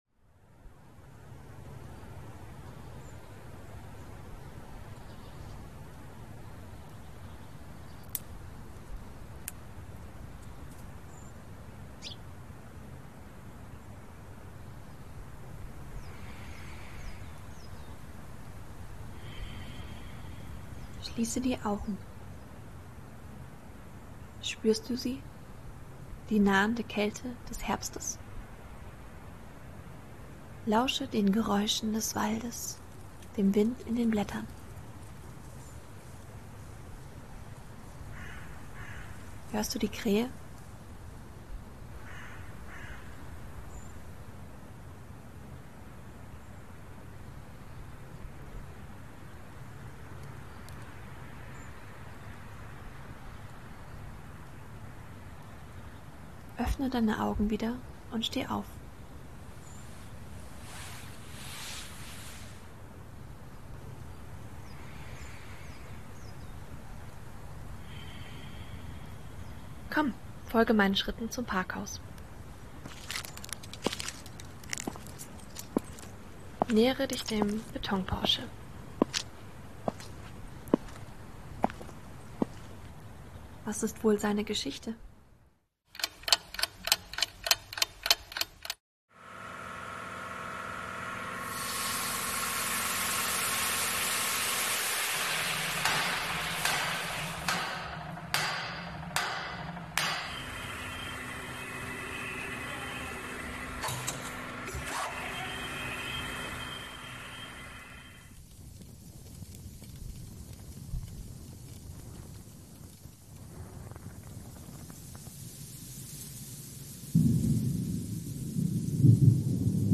Audiowalk – Kunst am Bau – LKM Facetten
Die Tonaufnahmen wurden von den Studierenden erstellt, Töne aus externen Quellen, die untenstehend aufgelistet sind: